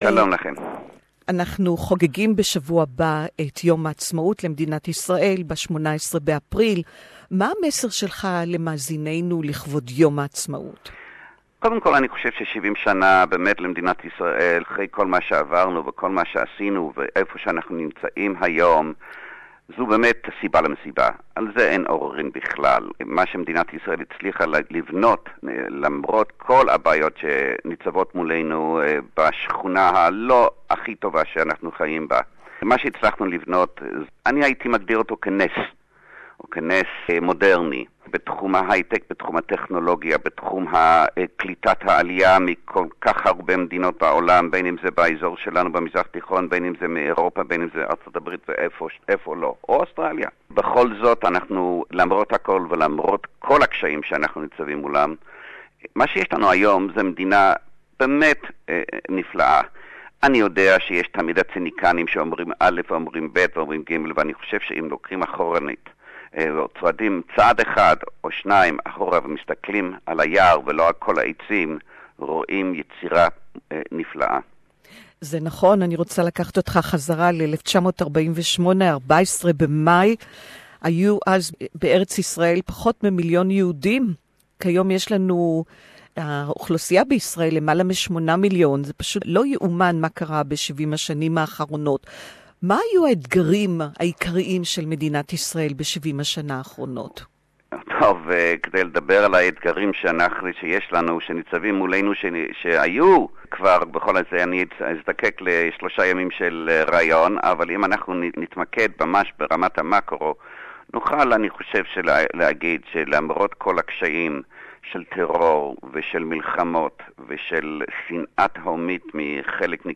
Israeli Ambassador, his Excellency Mark Sofer is celebrating Israel's 70th Birthday (Hebrew Interview)